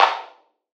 archived music/fl studio/drumkits/bvker drumkit/Claps